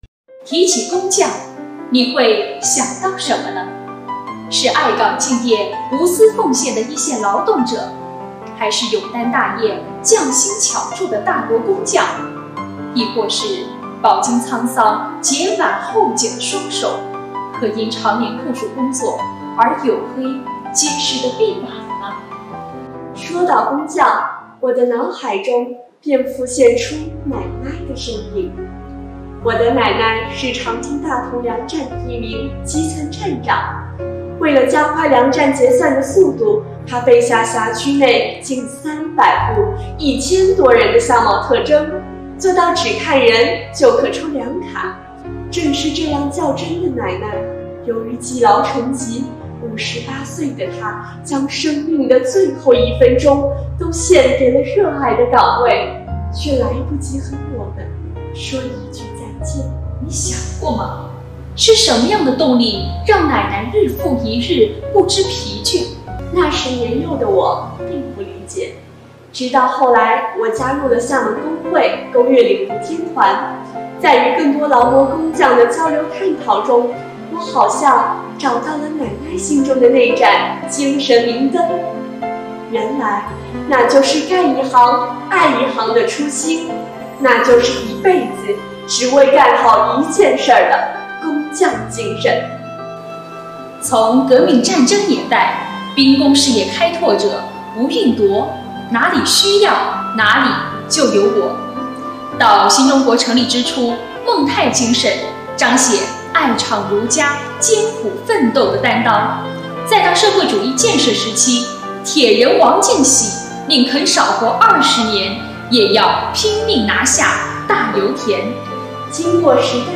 - 主题诵读作品 -